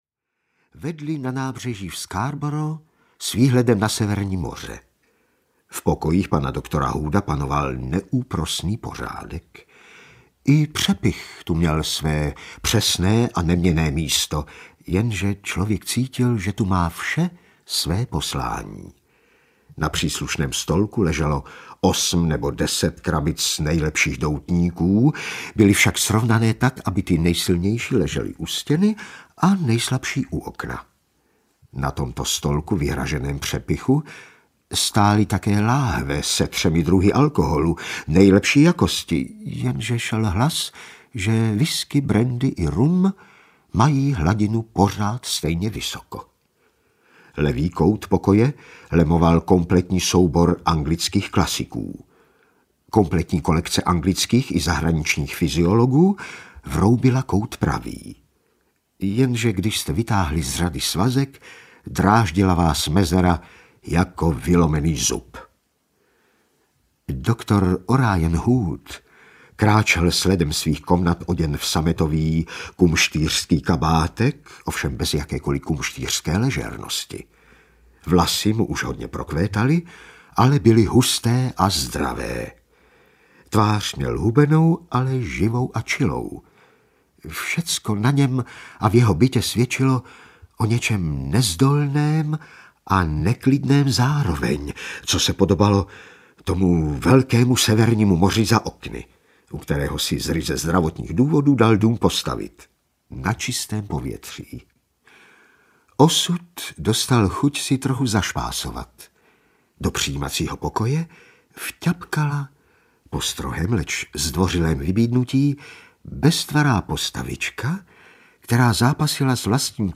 6x Otec Brown audiokniha
Záhady, které vyřeší katolický kněz a zároveň bystrý amatérský detektiv v podání Petra Pelzera.
Ukázka z knihy